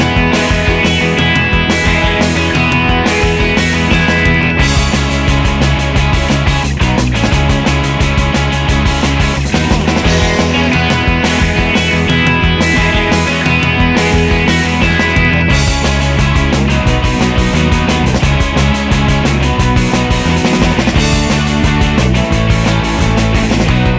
no Backing Vocals Punk 2:41 Buy £1.50